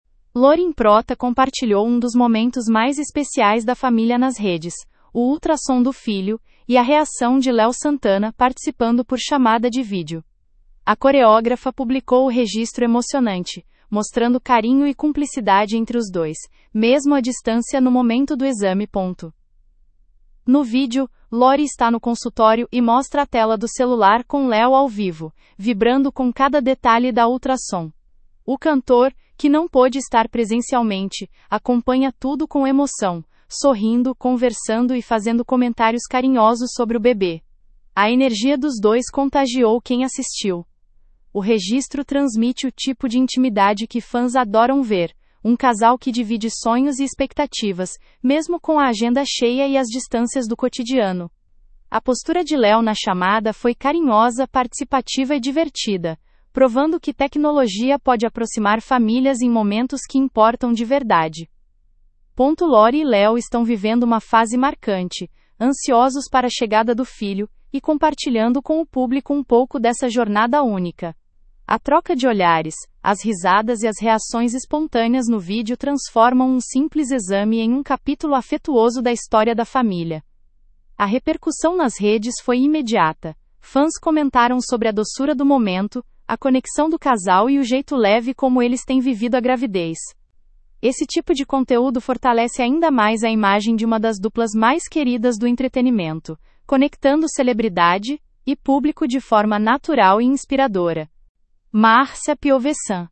No vídeo, Lore está no consultório e mostra a tela do celular com Léo ao vivo, vibrando com cada detalhe da ultrassom. O cantor — que não pôde estar presencialmente — acompanha tudo com emoção, sorrindo, conversando e fazendo comentários carinhosos sobre o bebê.